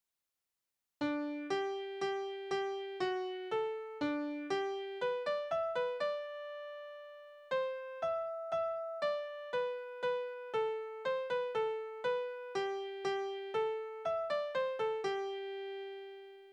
Besetzung: vokal